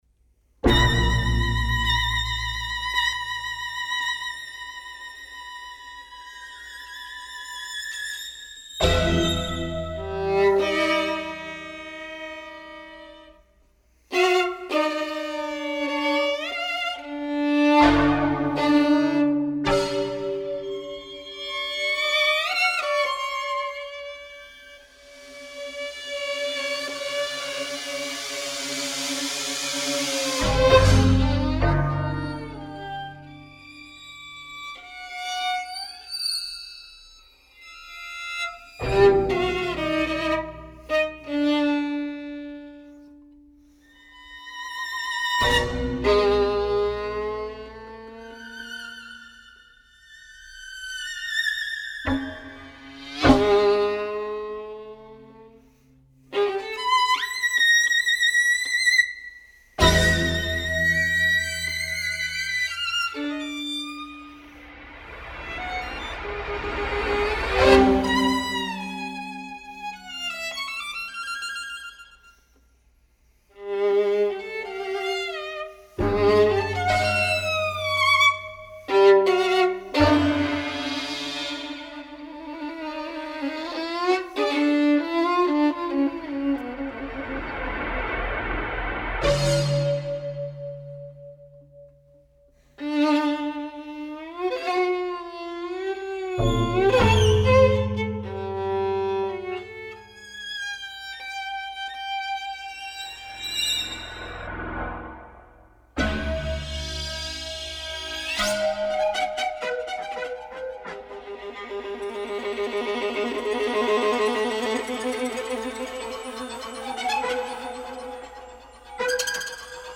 Para violín y sonidos fijos (1992/93)
Violín
Grabación del estreno en Nantes 1993.